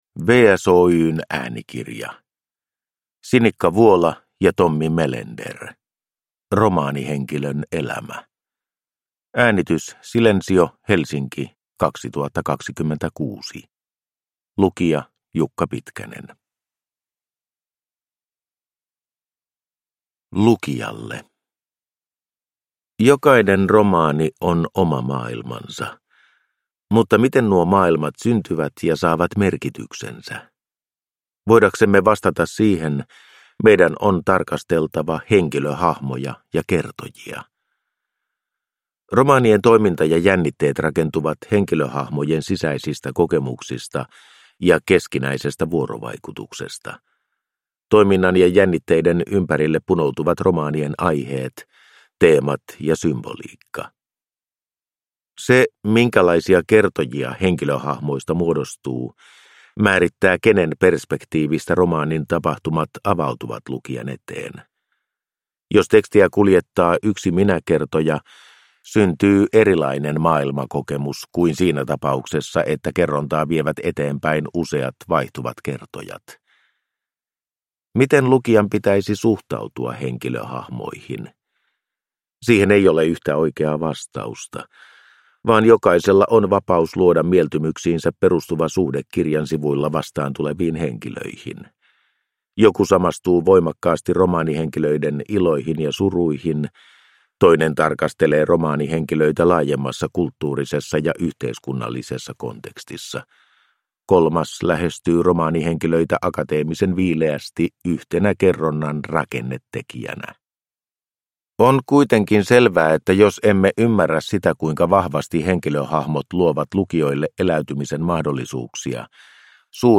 Romaanihenkilön elämä – Ljudbok